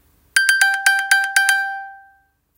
3. De iPhone geeft nu een kort ping-geluid, zodat je hem kunt vinden.
Klik hieronder op afspelen om te horen hoe het geluidje klinkt, zodat je beter weet waar je op moet letten.
iphone-pingen-apple-watch-geluidje.m4a